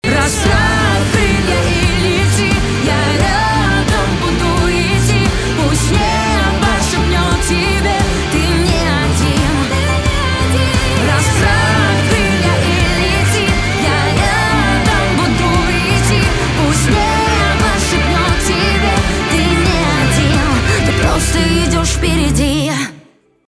поп
саундтреки
гитара , барабаны , чувственные